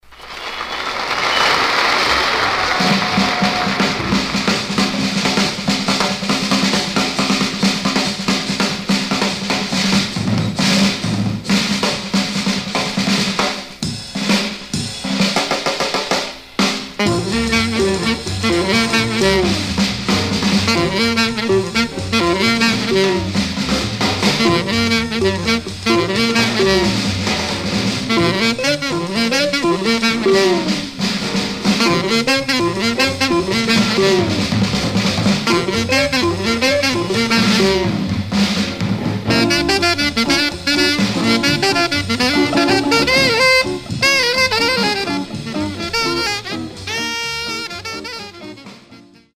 Mono
R&B Instrumental Condition